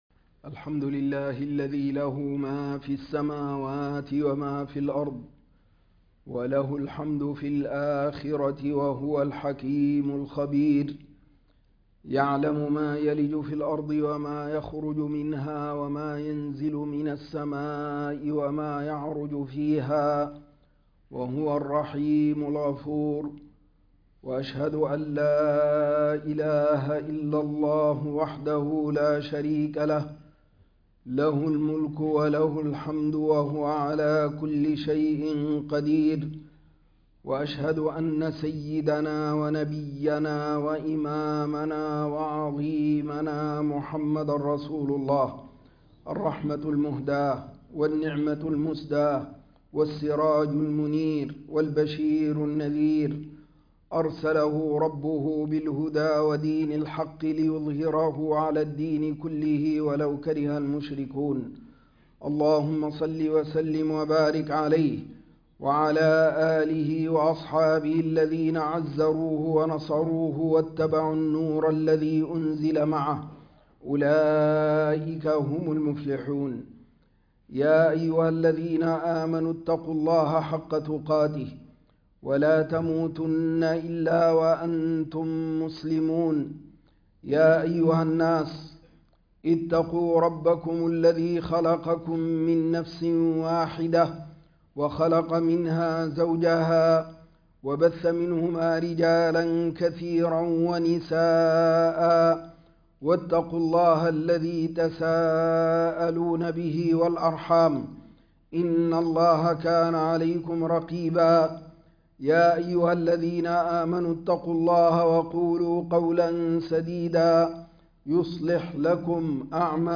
صفات الرجال _ خطبة الجمعة 3-1-2025